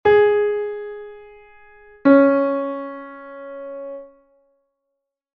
O intervalo sol# reb